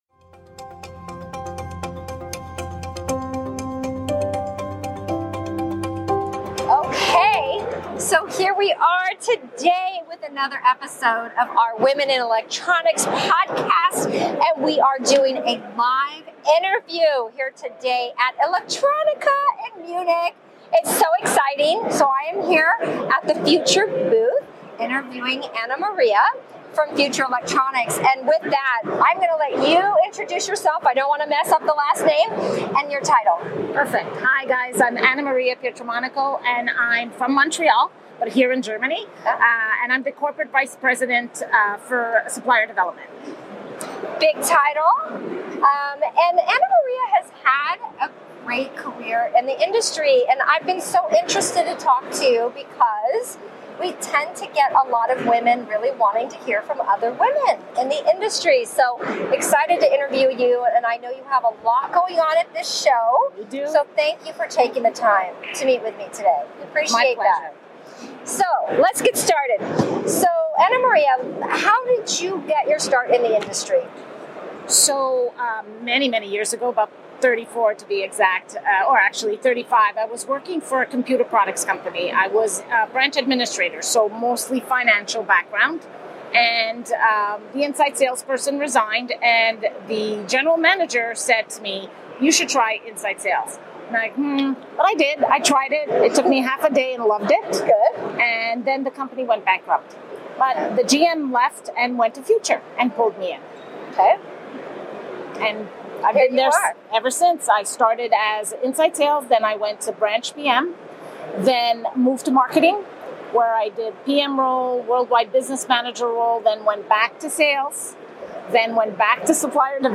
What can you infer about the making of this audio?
Live from electronica 2022 in Munich